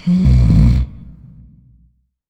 inhale.wav